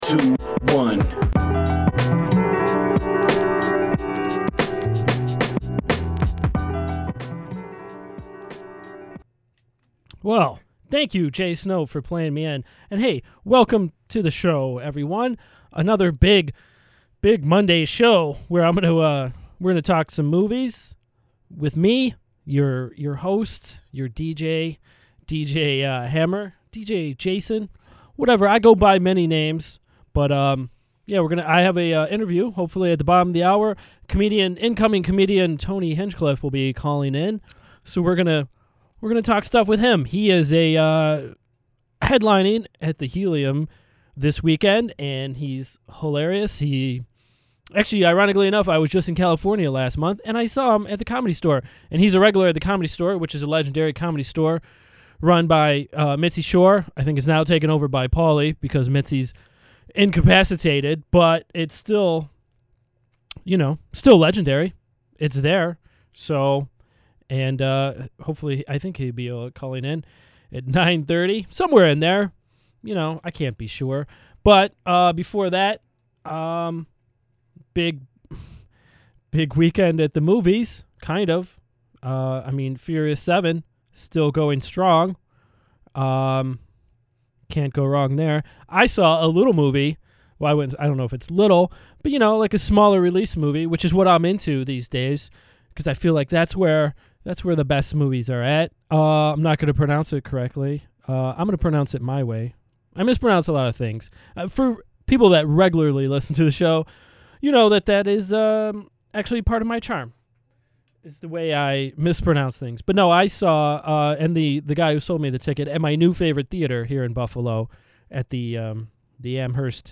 Episode 124: Comic Tony Hinchcliffe calls in and a review of Ex Machina
comedy interview